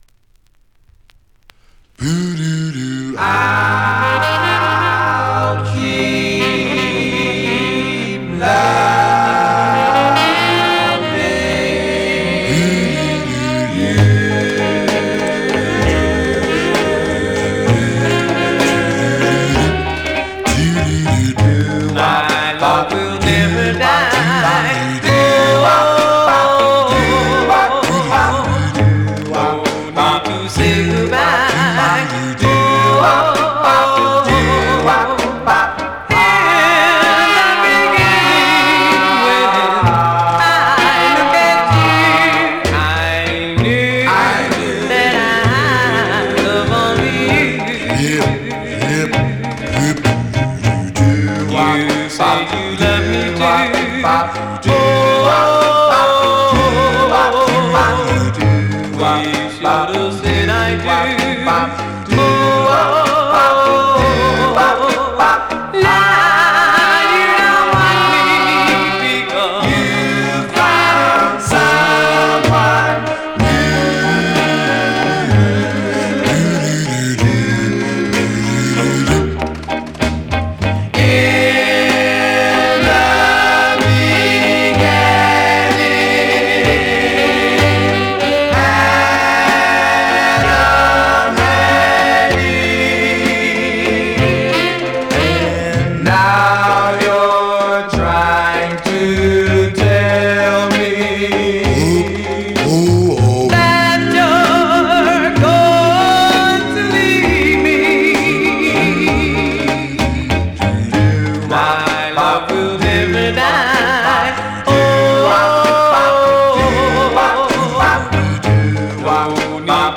Surface noise/wear Stereo/mono Mono
Male Black Group